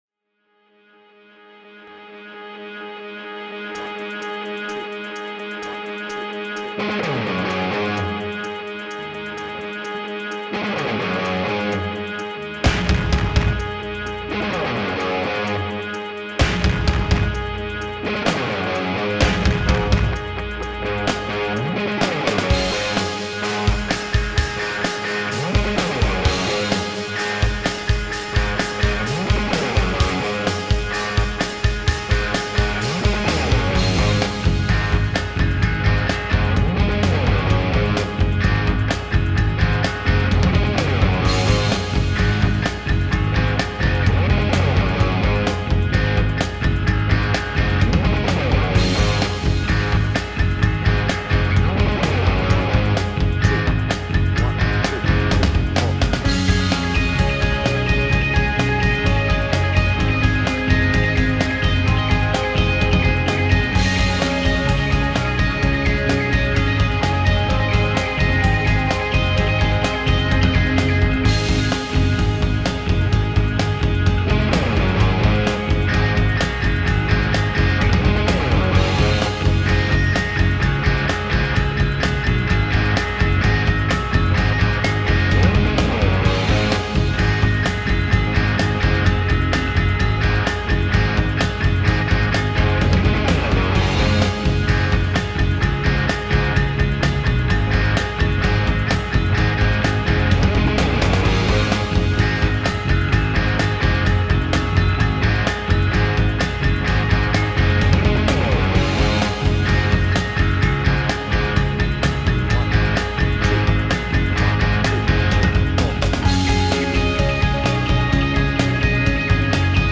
BPM : 128
Tuning : Eb
Without vocals